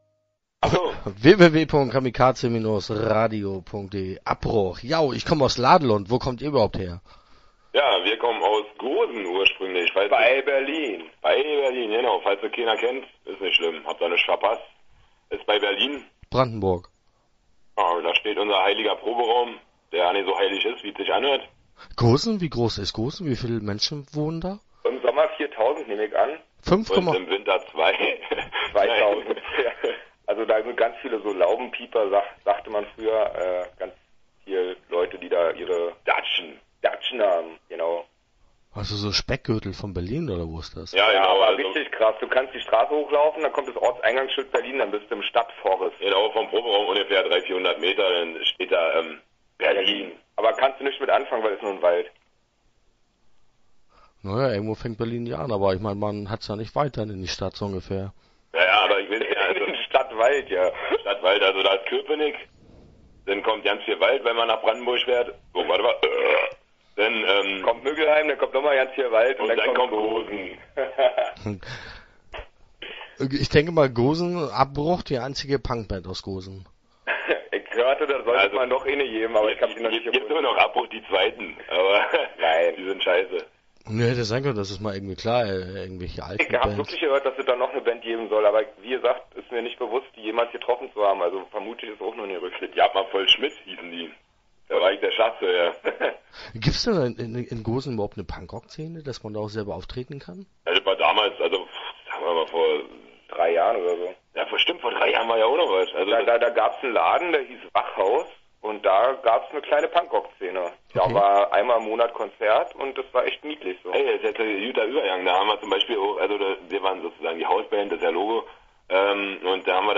Start » Interviews » Abbruch